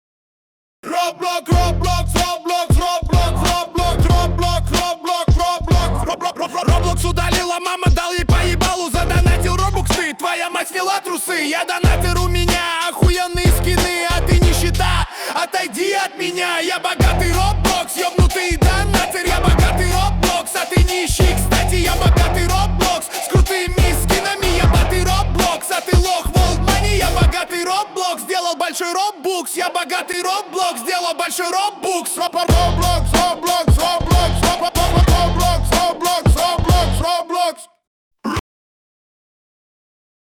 хип-хоп/рэп